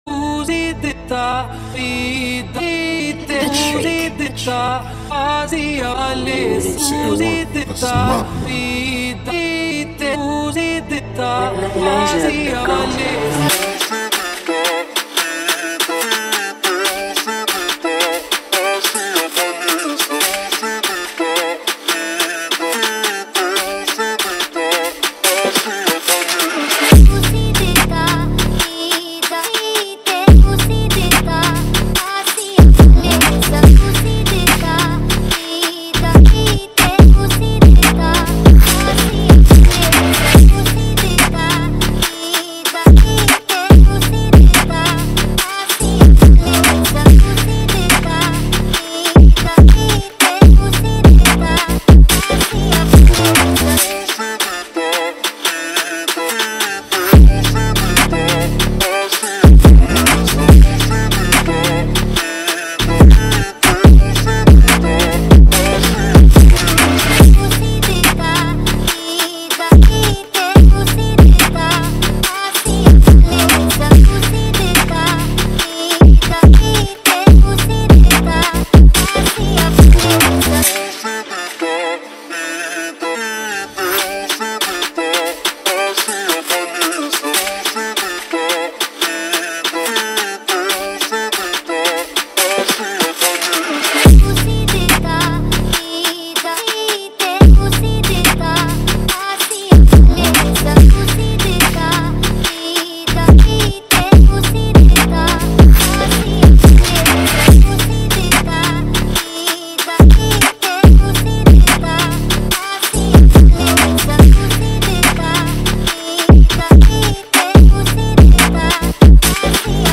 drill instrumental
With its pulsating rhythms and dynamic soundscapes